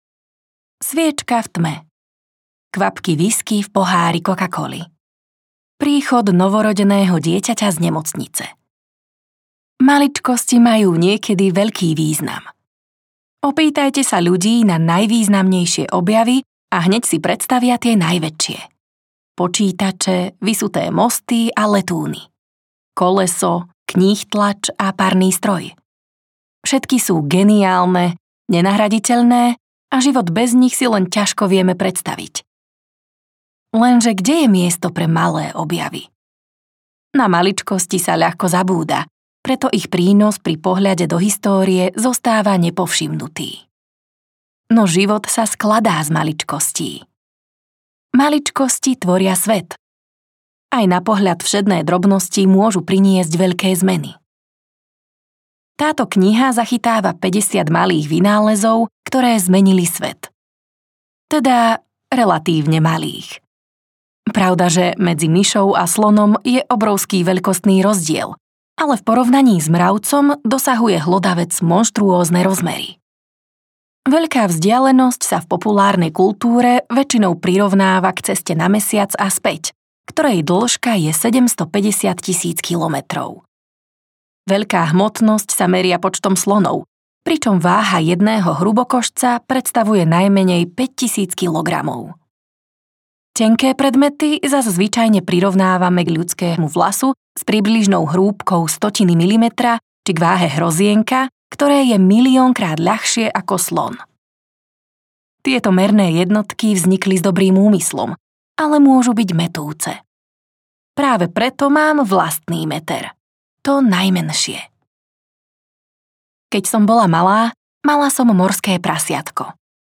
Malé vynálezy, ktoré zmenili svet audiokniha
Ukázka z knihy
male-vynalezy-ktore-zmenili-svet-audiokniha